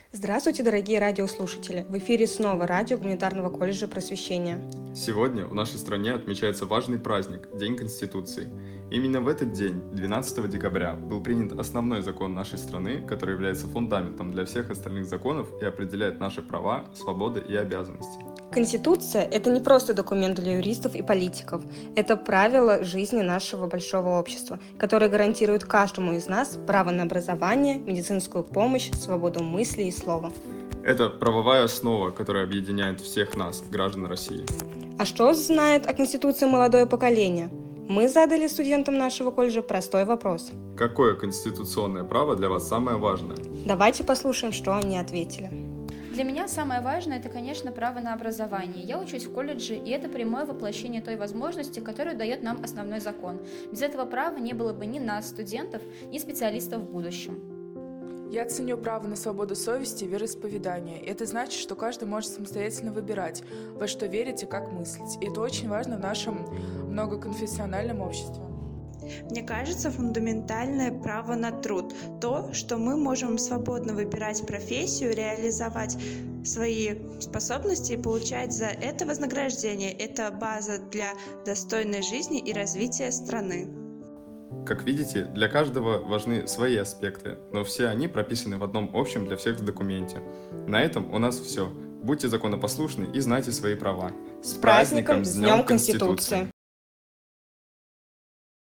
Дорогие друзья! Предлагаем вашему вниманию радиовыпуск (подкаст) студенческого радио Гуманитарного колледжа «Просвещение» ко Дню конституции: